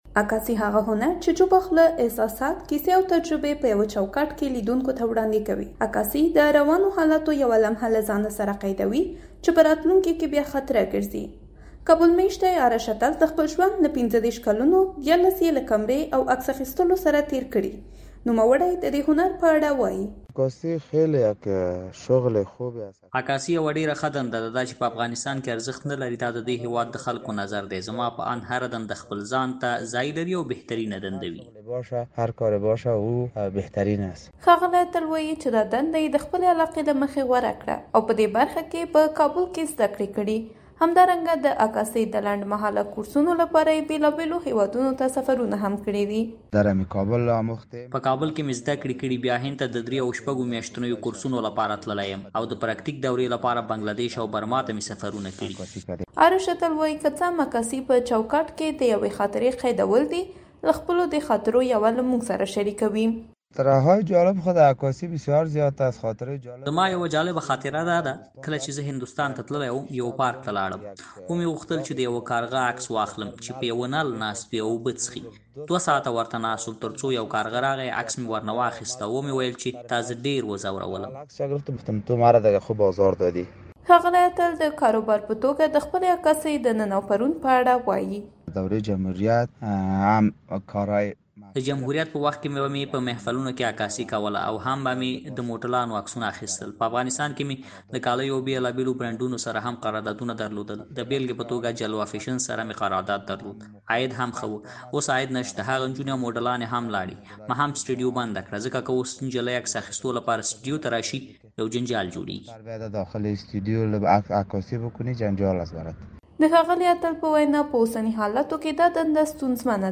د عکاسۍ راپور